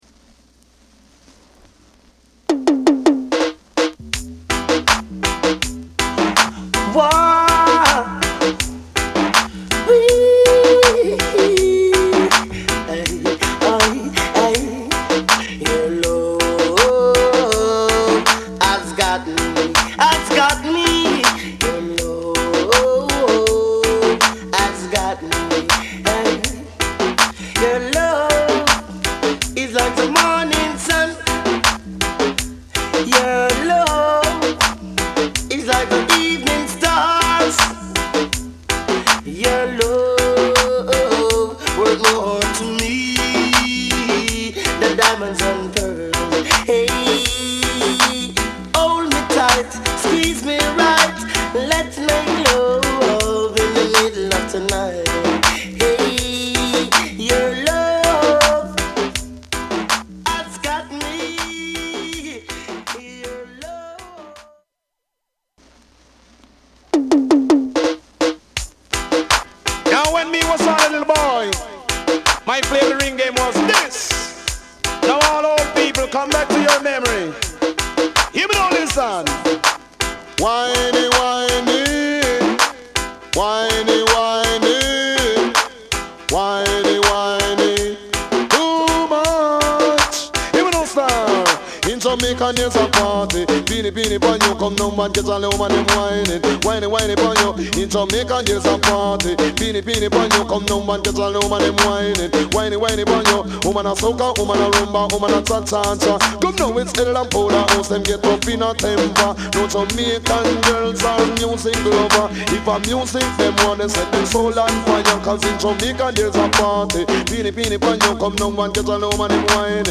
REGGAE / DANCEHALL